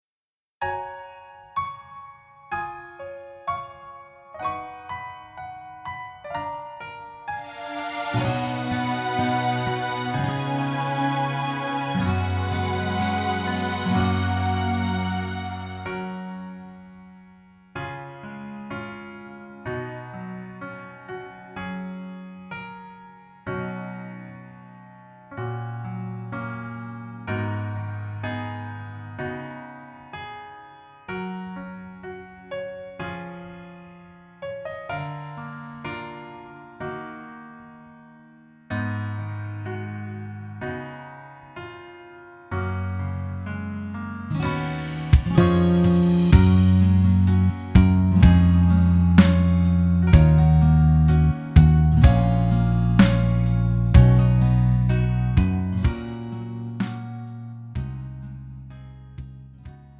음정 여자키
장르 가요 구분 Pro MR